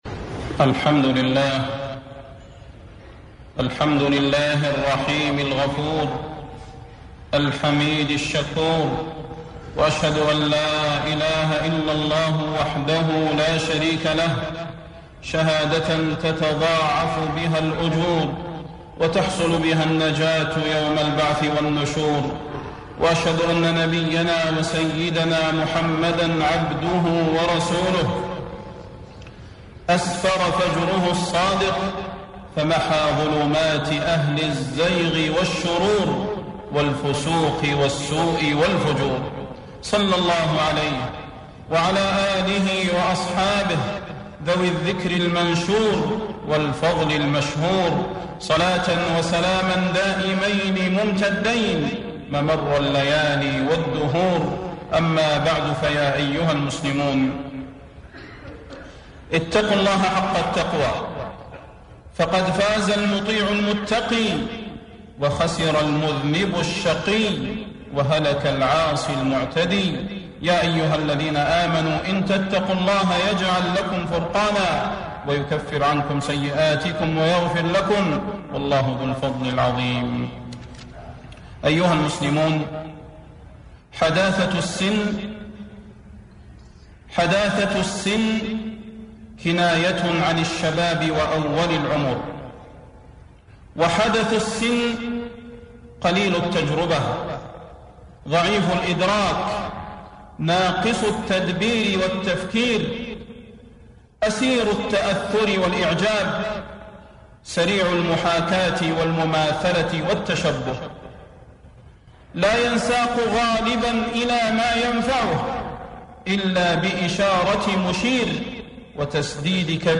خطب الحرم المكي
ضياع شبابنا 4 ربيع الأول 1433 الشيخ صلاح البدير حفظ المادة MP3